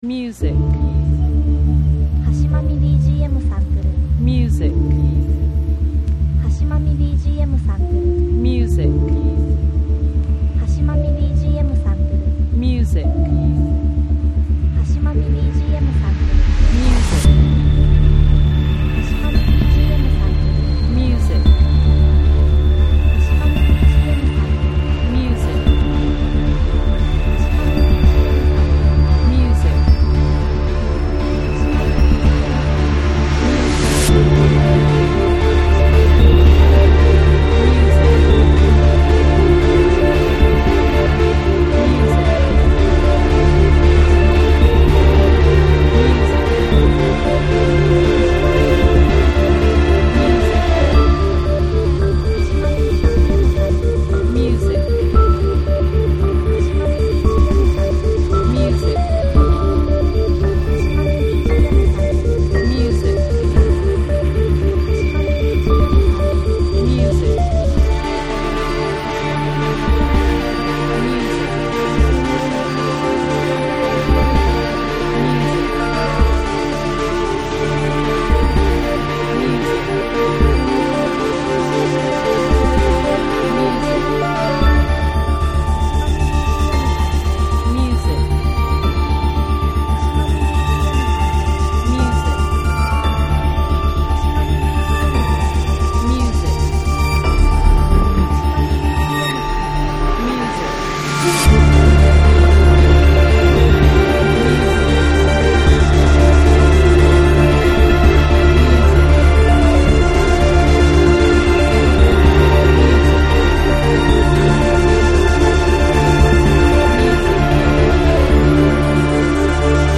緊迫した雰囲気のミステリアスなダーク曲